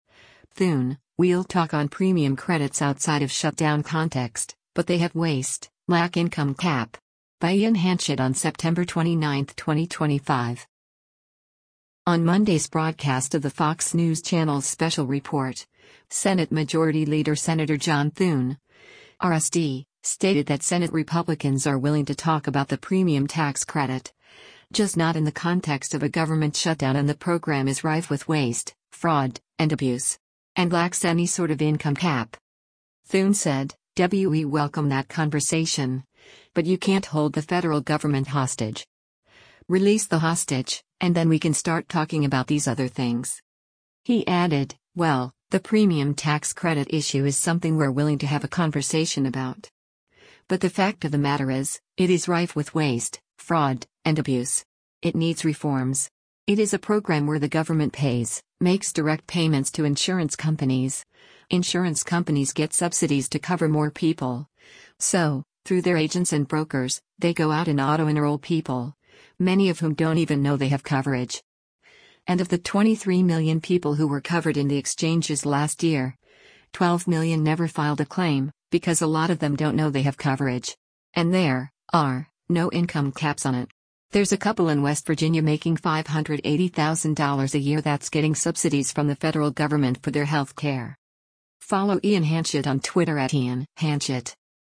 On Monday’s broadcast of the Fox News Channel’s “Special Report,” Senate Majority Leader Sen. John Thune (R-SD) stated that Senate Republicans are willing to talk about the premium tax credit, just not in the context of a government shutdown and the program “is rife with waste, fraud, and abuse.”